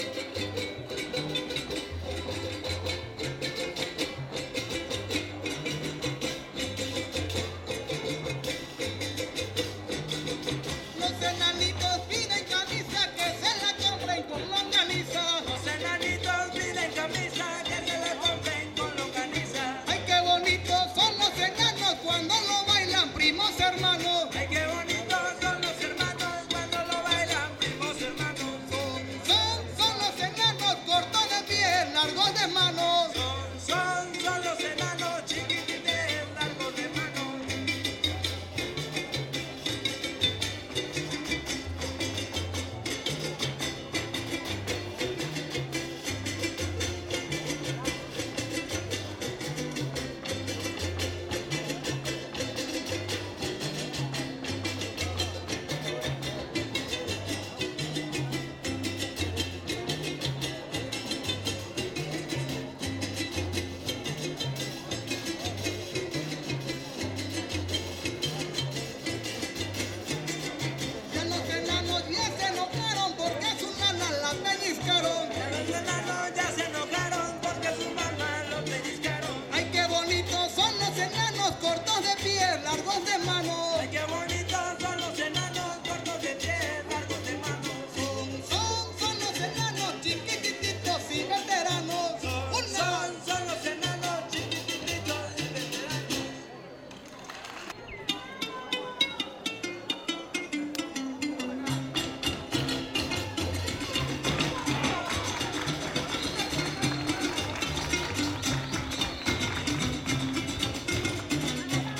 • Siquisirí (Grupo musical)
Concurso Estatal de Fandango